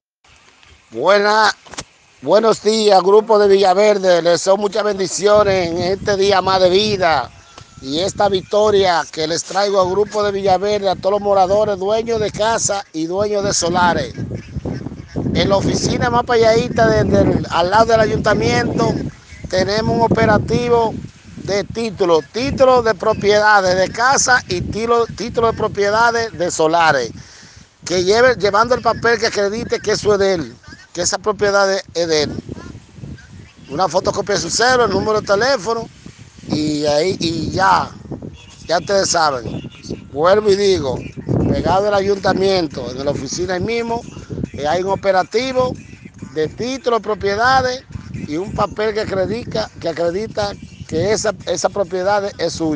Como se puede escuchar en los mensajes, una persona, quien se identifica como aspirante a regidor por SDO para los próximos comicios electorales, refiere que estarán recibiendo documentación de los propietarios en un punto especifico, cerca del Ayuntamiento, razon pr la que muchos moradores están yendo con sus documentos y una inmensa multitud ya ha sido atendida, algunos han dicho que les han solicitado dinero para el proceso de validación de sus títulos.